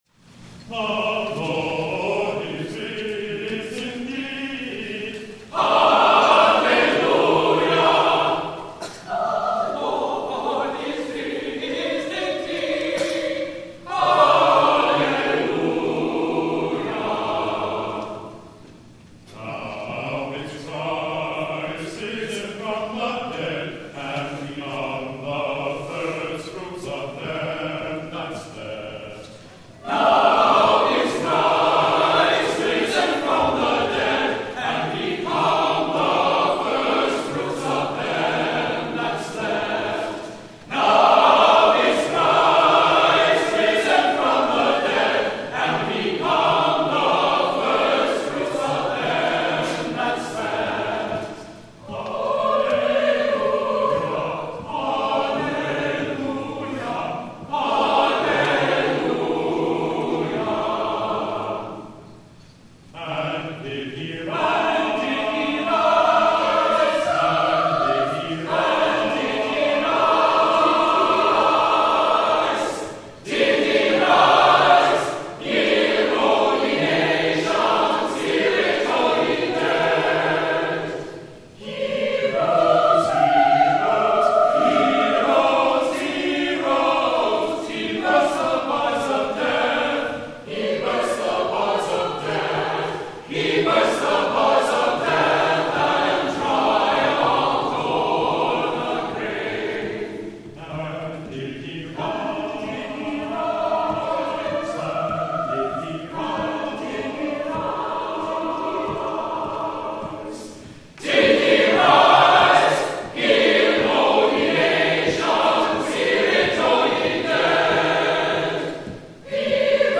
Easter Sunday
THE ANTHEM
(11:00 a.m. worship)